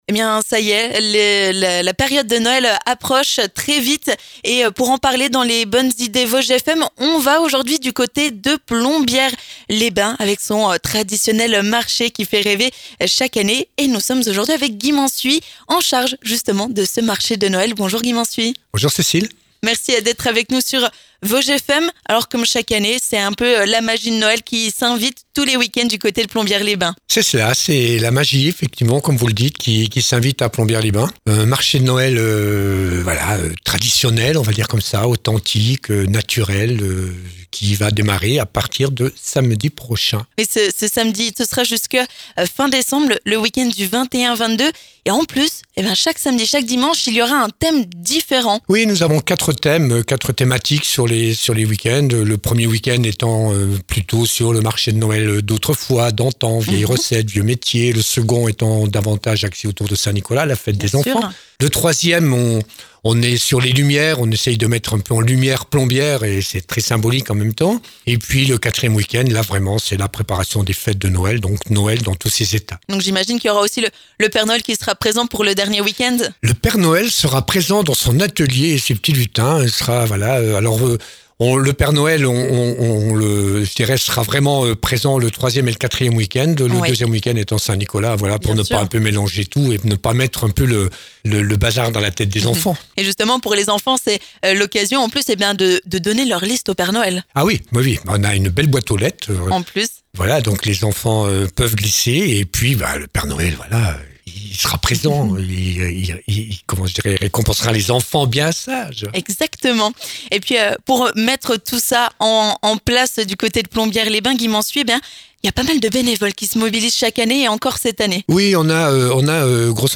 On vous en dit plus dans les Bonnes Idées Vosges FM avec Guy Mansuy, adjoint à la municipalité de Plombières-les-Bains en charge du marché de Noël.